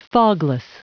Prononciation du mot fogless en anglais (fichier audio)
Prononciation du mot : fogless